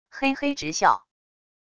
嘿嘿直笑wav音频